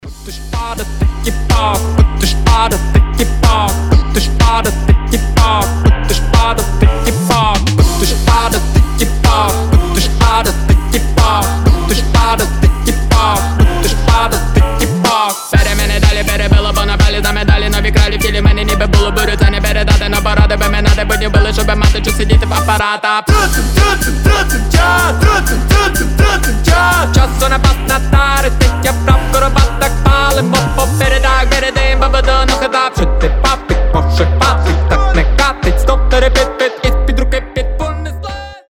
• Качество: 320, Stereo
ритмичные
Хип-хоп
забавные
речитатив
Весёлый украинский хип-хоп